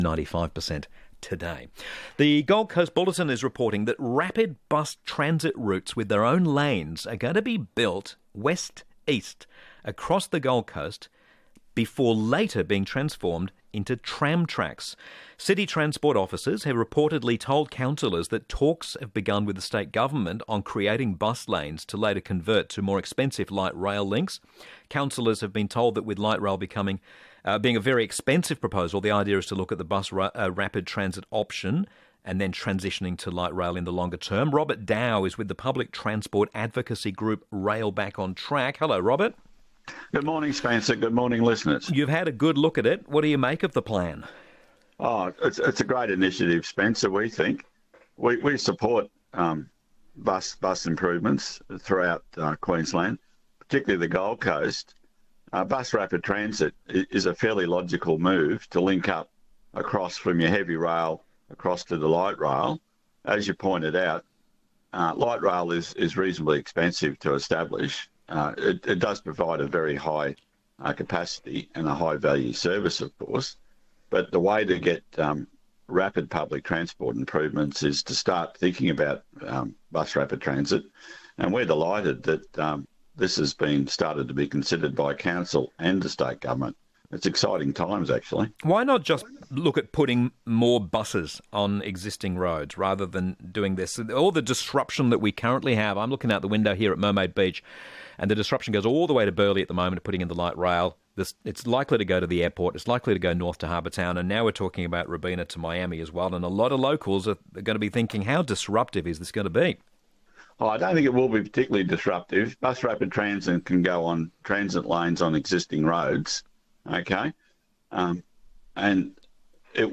Interview 28th September 2024